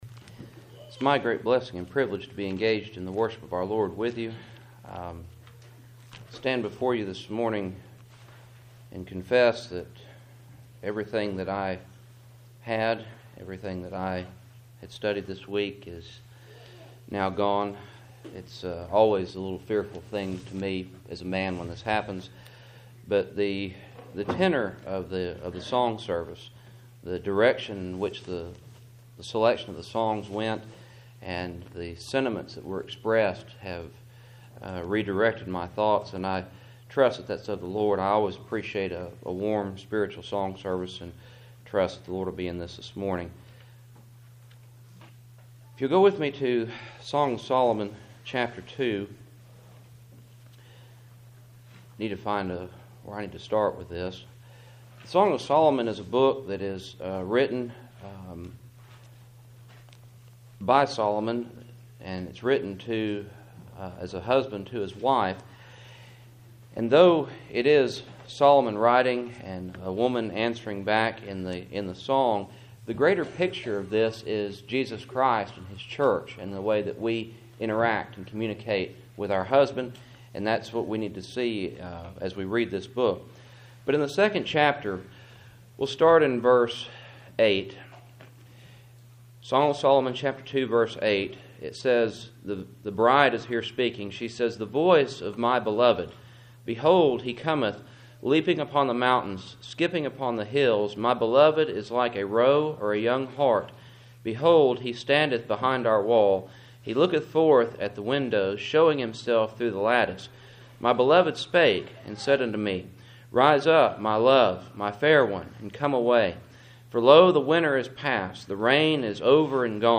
Passage: Song of Solomon 2:8-13 Service Type: Cool Springs PBC Sunday Morning